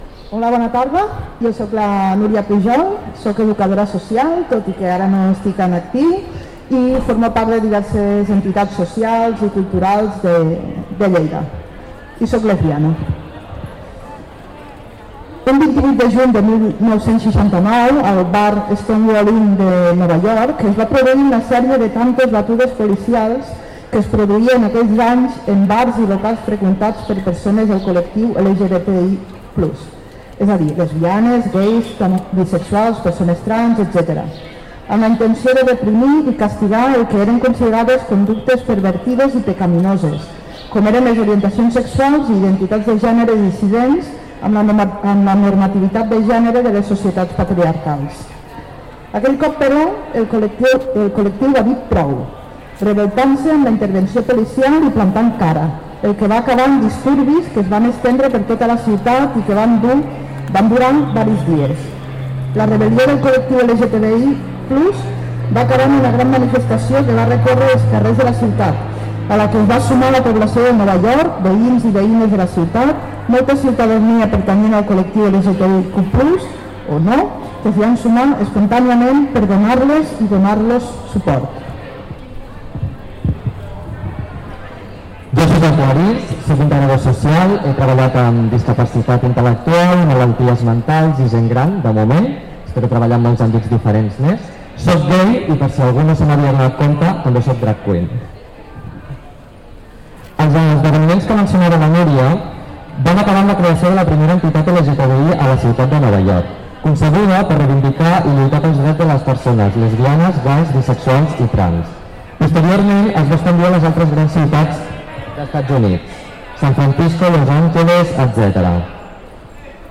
Acte institucional aquesta tarda a la plaça de la Paeria amb la Lectura del manifest per part de membres del col·lectiu LGBTI+